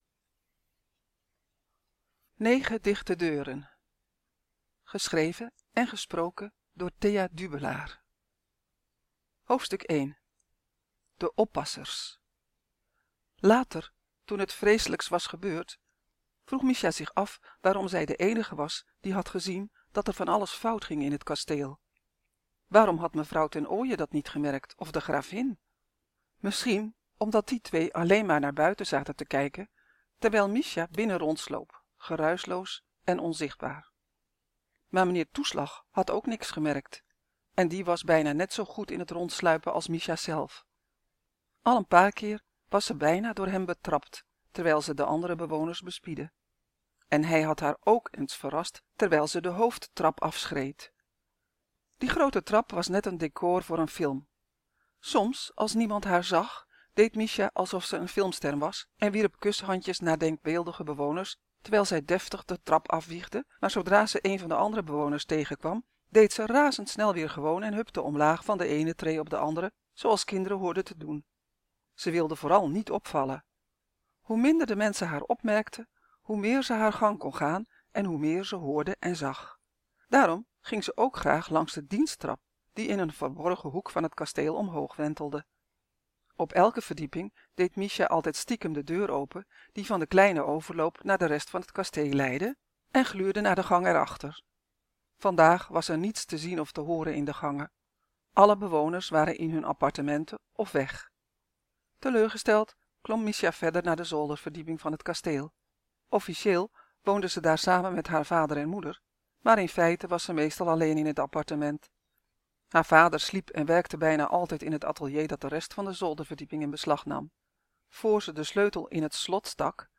Negen dicht deuren is er als boek maar nog niet helemaal als luisterboek.